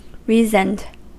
Ääntäminen
Ääntäminen US Haettu sana löytyi näillä lähdekielillä: englanti Reasoned on sanan reason partisiipin perfekti.